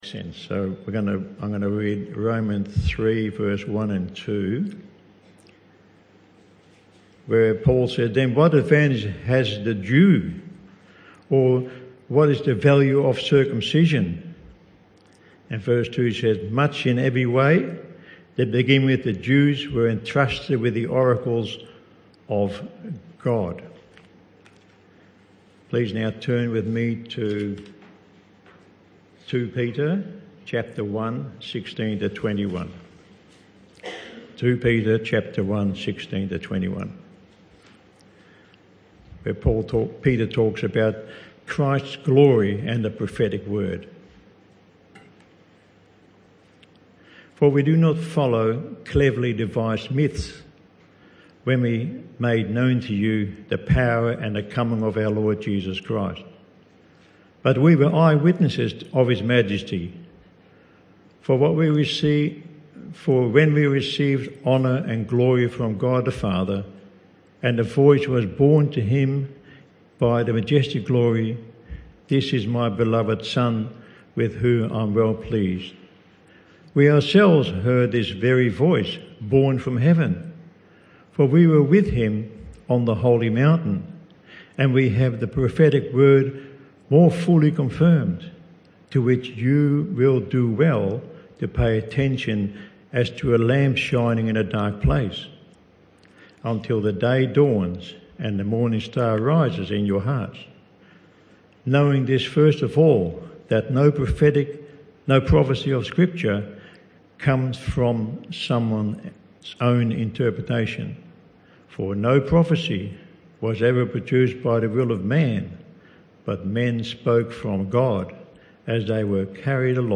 This talk was part of the AM Service series entitled He Has Spoken (Talk 2 of 3).